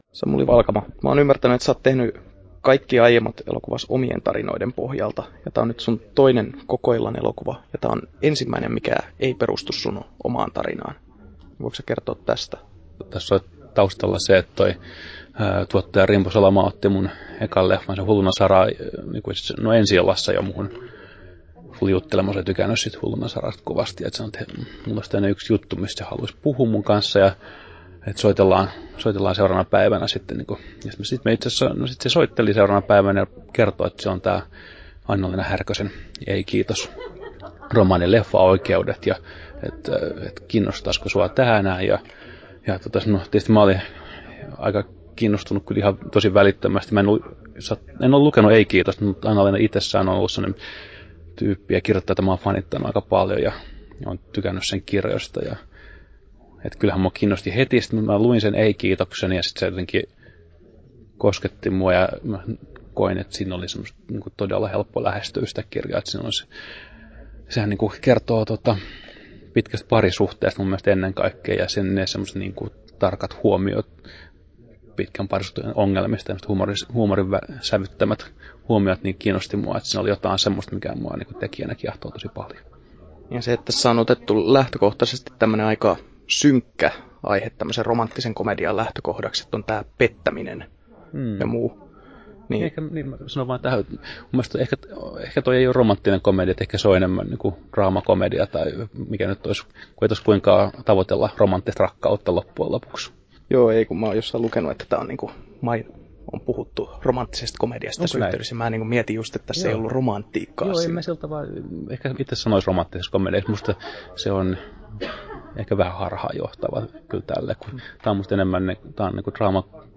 7'10" Tallennettu: 20.12.2013, Turku Toimittaja